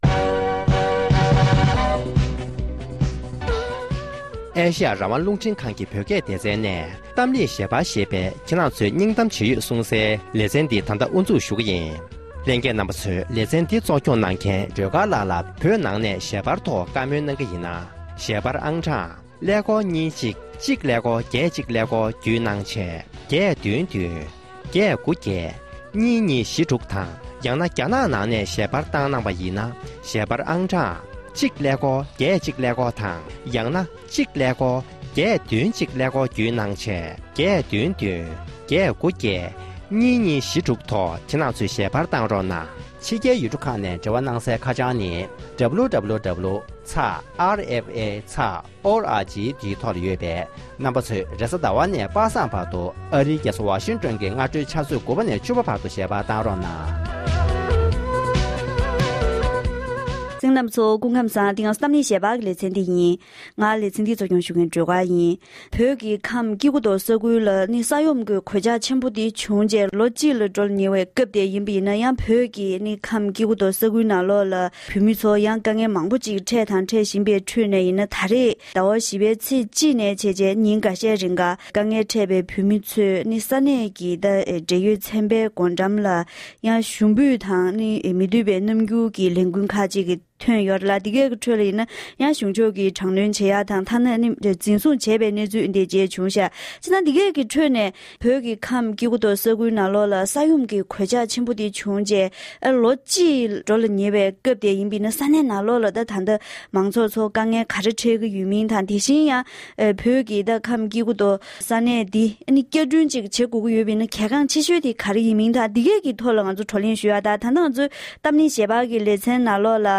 གཏམ་གླེང་ཞལ་པར་ལེ་ཚན